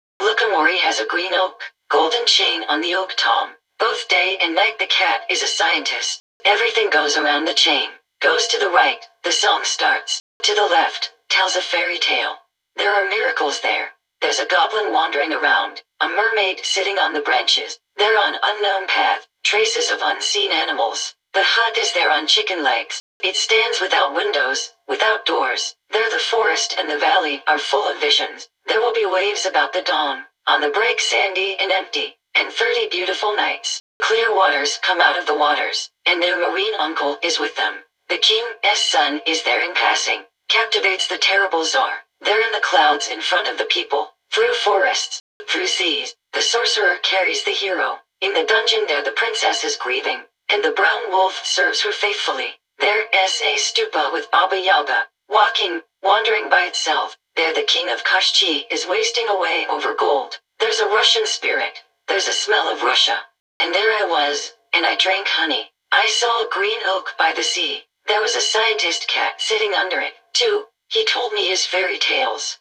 Fallout_4 / English /F4_F_DLC01RobotCompanionFemaleDefault_Eng /OLD /G_65000 (Eng).wav